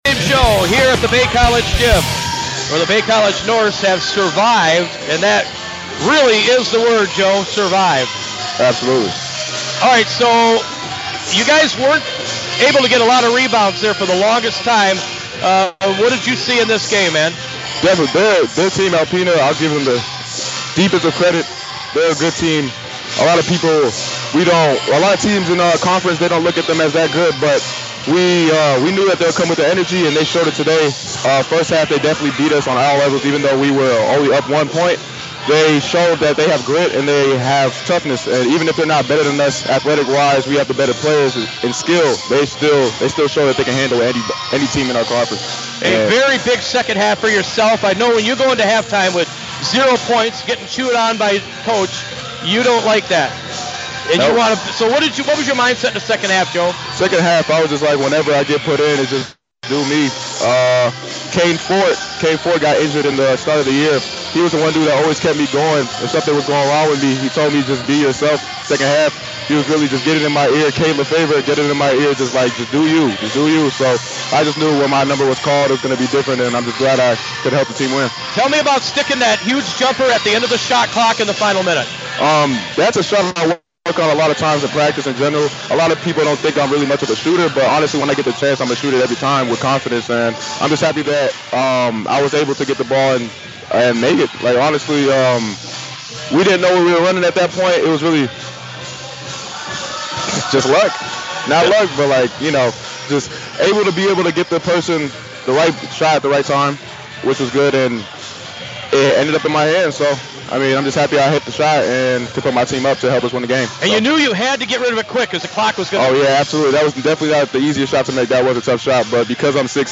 post-game coimments